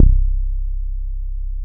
COOL BASS 1.wav